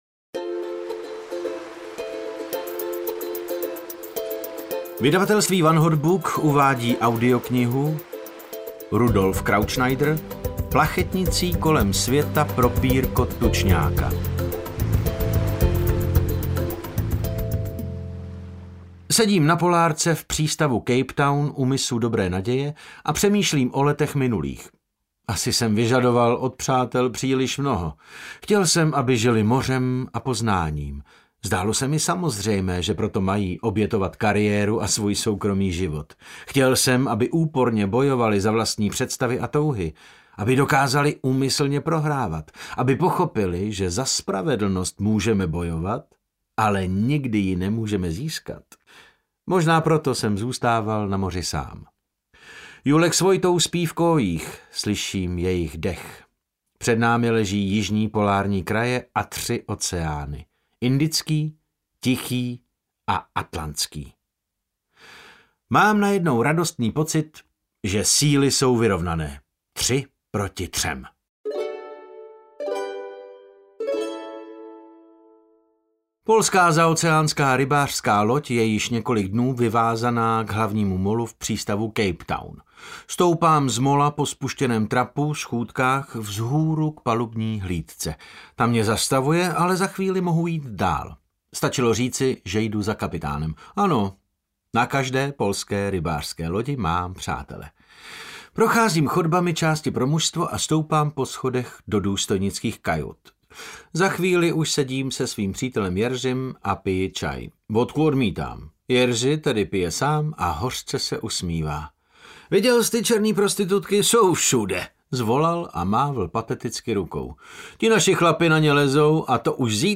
Audio knihaPlachetnicí kolem světa pro pírko tučňáka
Ukázka z knihy
• InterpretDavid Novotný